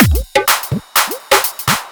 125 BPM Beat Loops Download